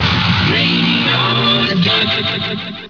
The jingles